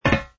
fs_ml_steel04.wav